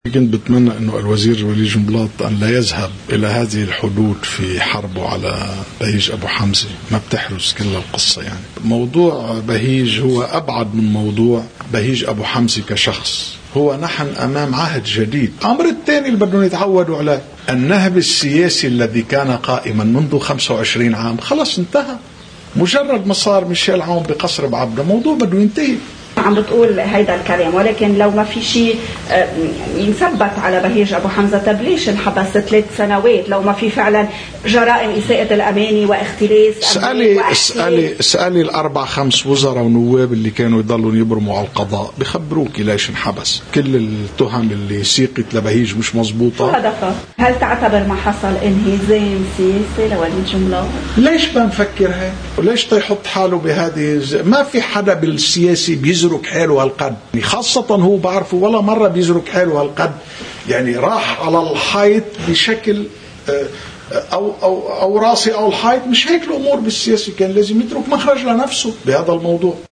مقتطف من حديث وئام وهاب لقناة الـ”OTV”